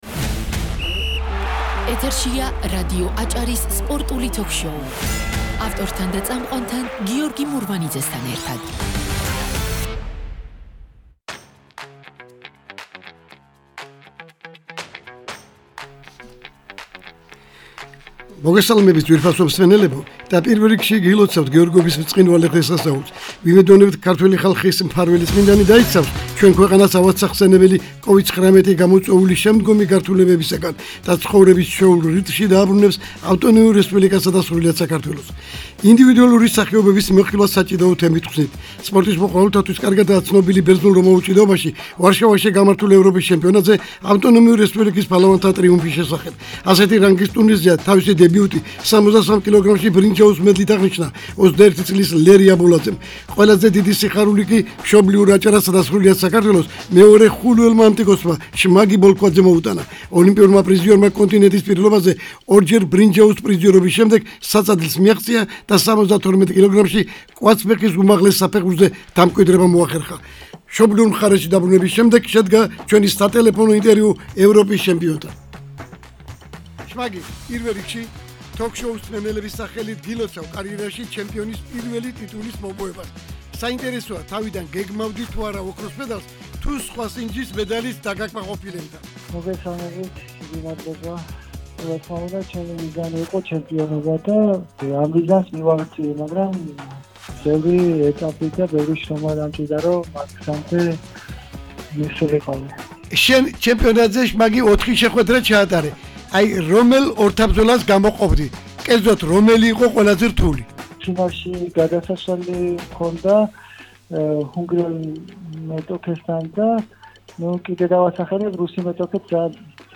სპორტული სიახლეები და ინტერვიუ